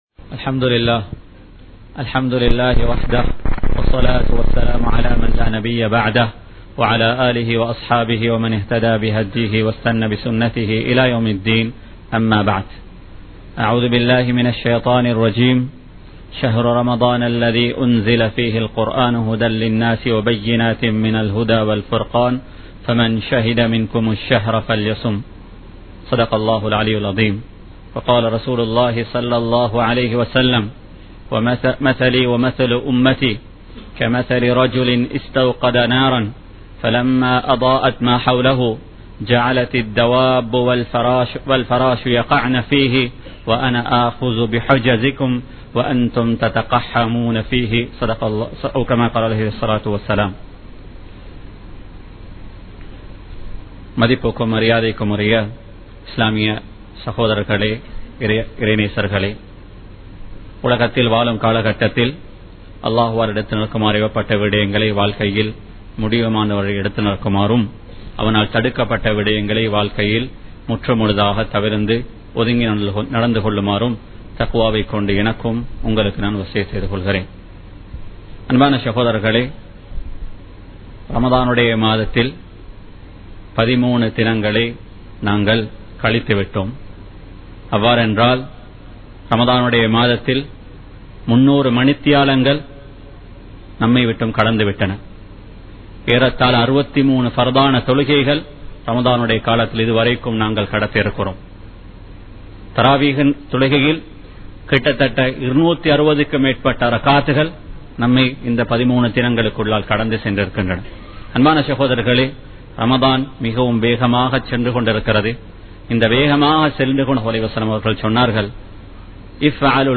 மாற்றத்திற்கான நேரம் ரமழான் | Audio Bayans | All Ceylon Muslim Youth Community | Addalaichenai
Kollupitty Jumua Masjith